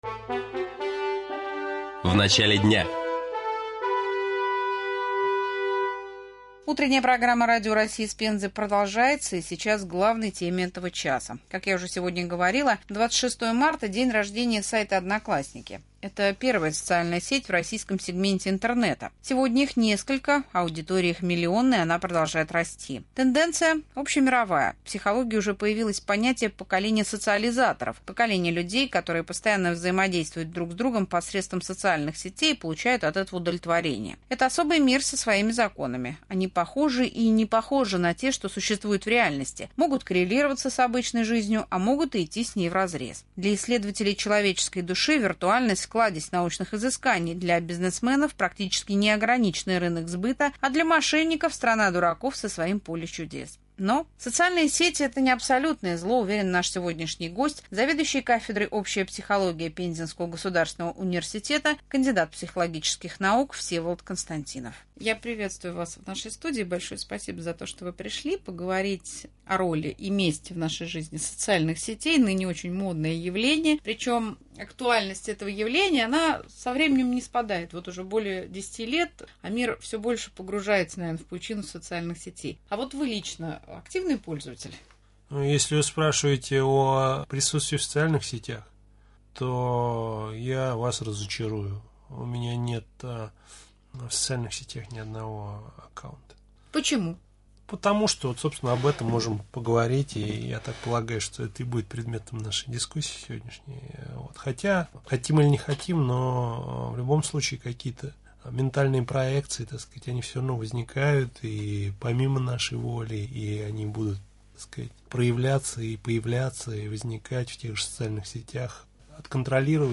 Интервью зведующего кафедрой